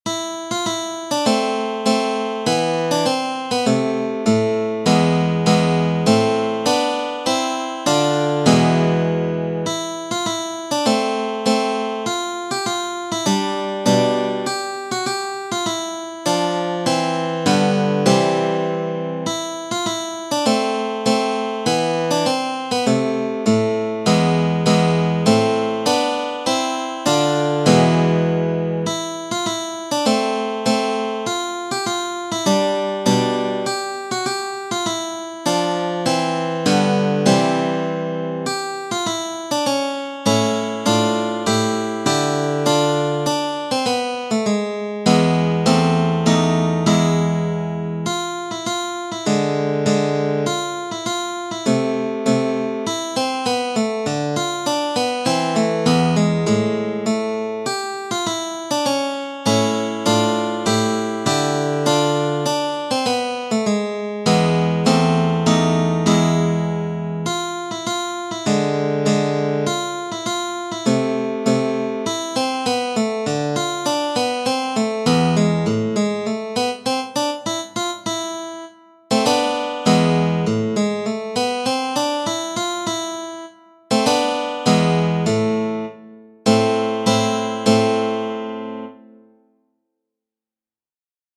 Metodo – pag. 39 – Romance – in Lam